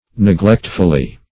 [1913 Webster] -- Neg*lect"ful*ly, adv. --